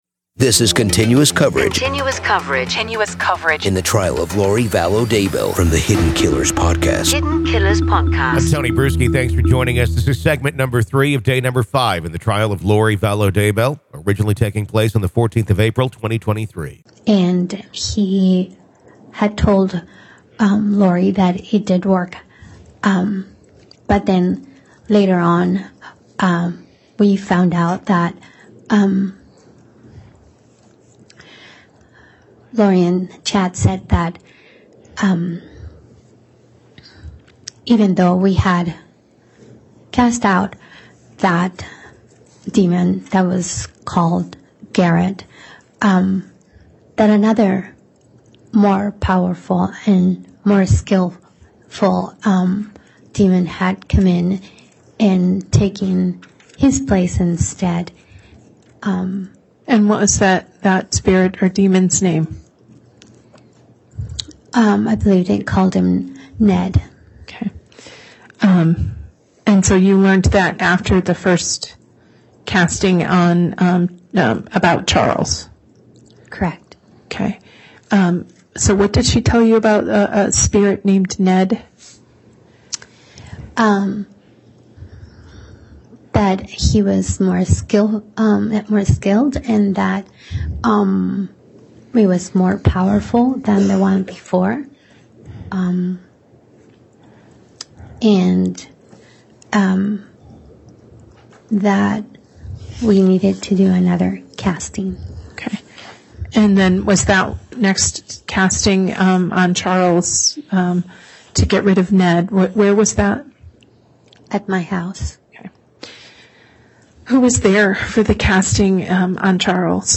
The Trial Of Lori Vallow Daybell Day 5 Part 3 | Raw Courtroom Audio